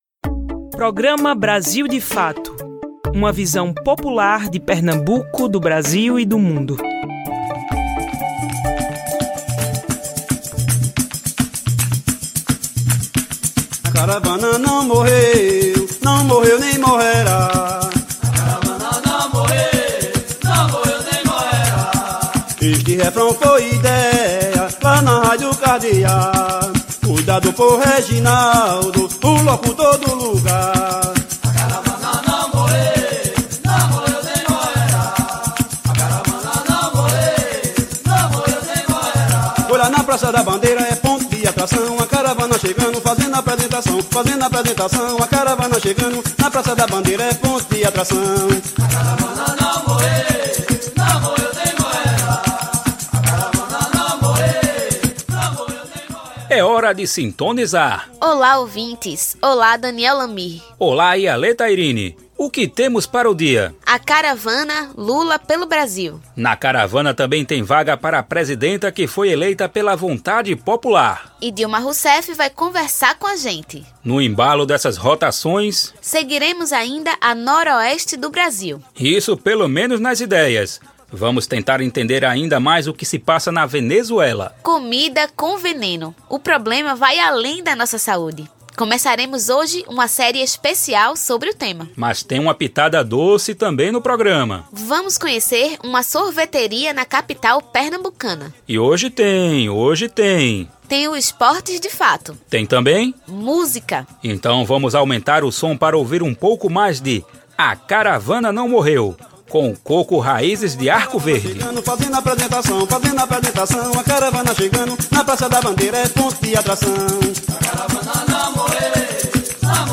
Golpe completa um ano e trazemos entrevista especial com a presidenta Dilma Rousseff